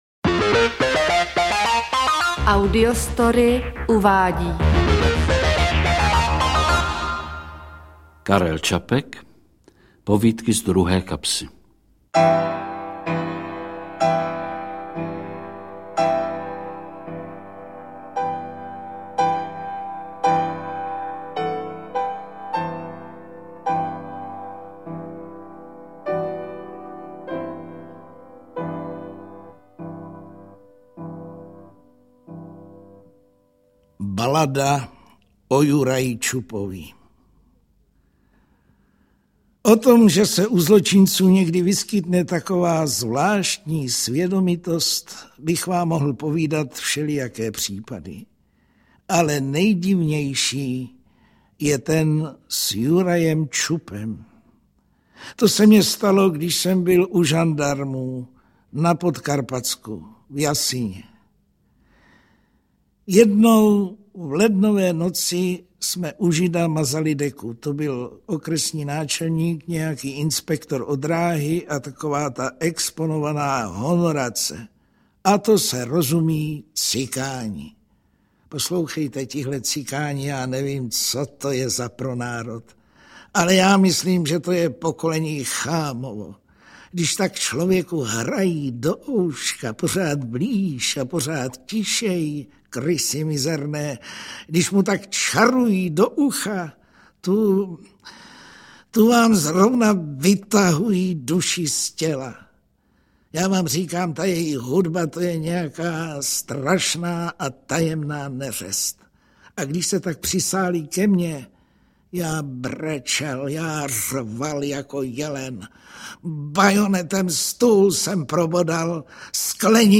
Interpreti:  Vlastimil Brodský, Ota Sklenčka
V podání Vlastimila Brodského a Oty Sklenčky.
AudioKniha ke stažení, 5 x mp3, délka 1 hod. 26 min., velikost 78,5 MB, česky